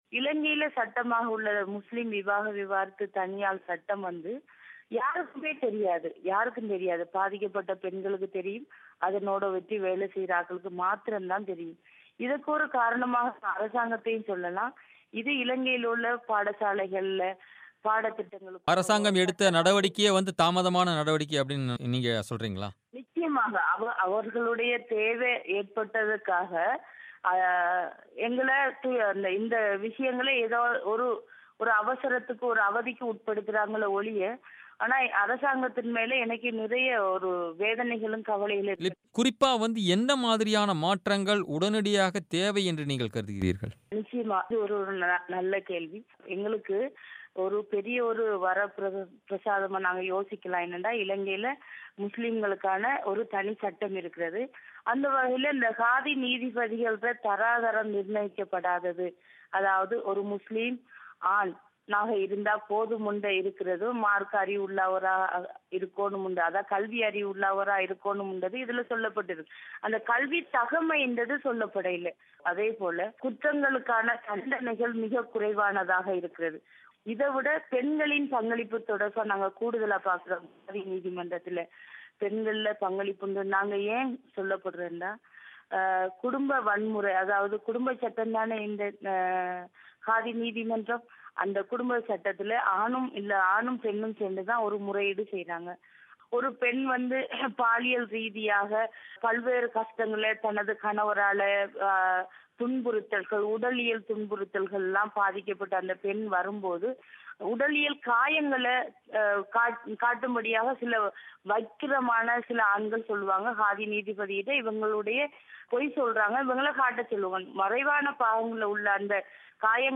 பிபிசி தமிழோசைக்கு அளித்த பேட்டி.